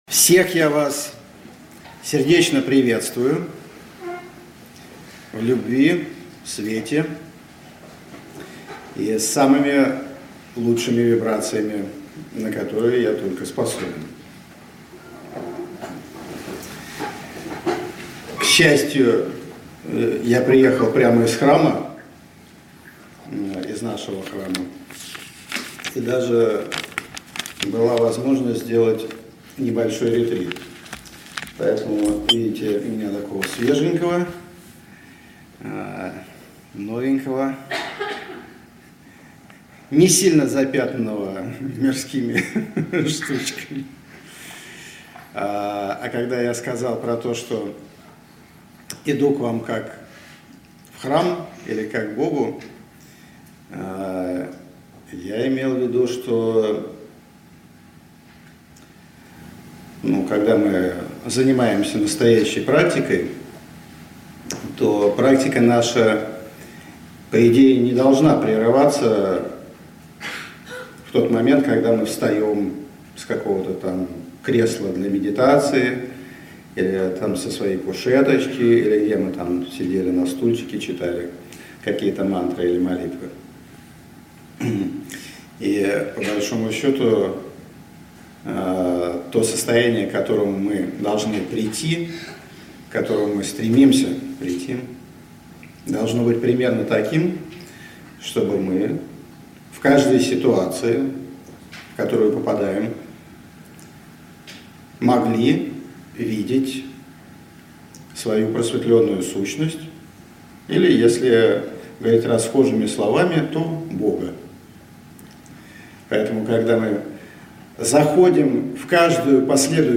Аудиокнига Возвращение к истокам | Библиотека аудиокниг